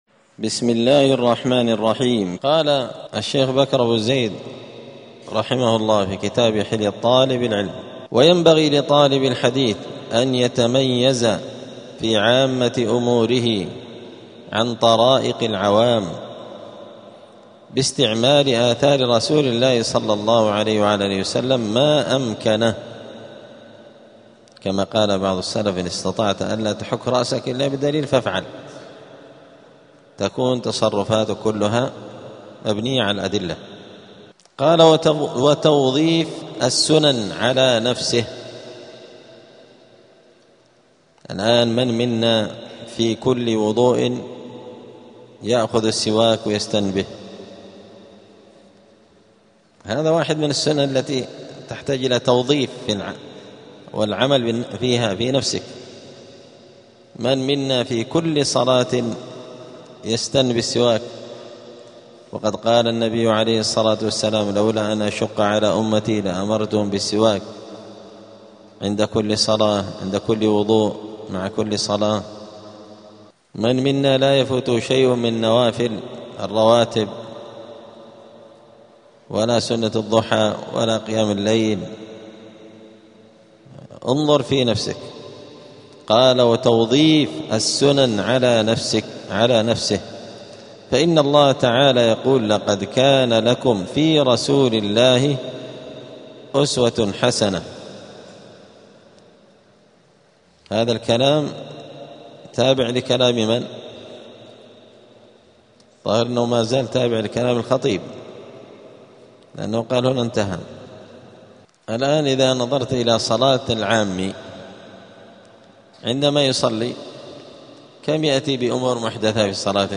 الجمعة 21 جمادى الآخرة 1447 هــــ | الدروس، حلية طالب العلم، دروس الآداب | شارك بتعليقك | 16 المشاهدات